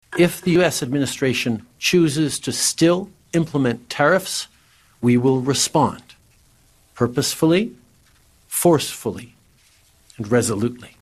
At a press conference, the minister and Prime Ministers talked about Canada coming together with the looming threat of a 25 per cent tariff on all Canadian goods beginning on Jan. 20, inauguration day – with Trudeau saying Canada will be ready if that happens.